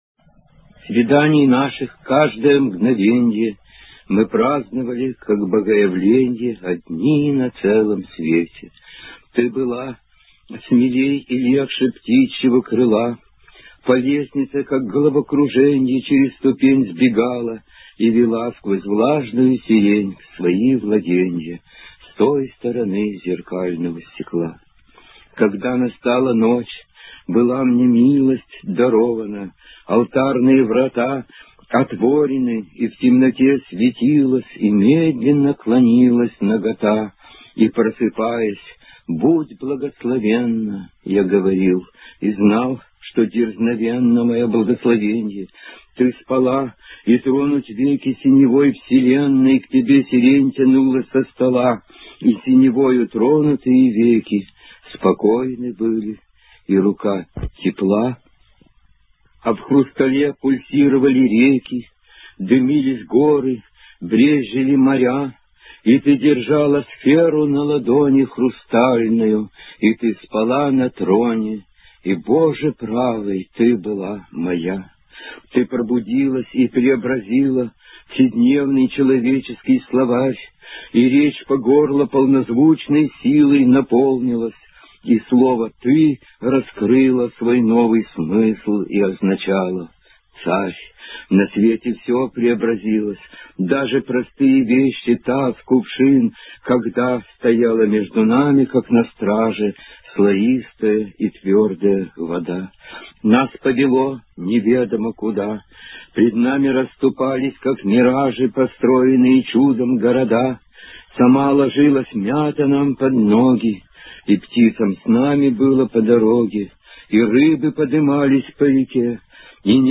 Марии Фальц, читает автор — Арсений Тарковский (скачать)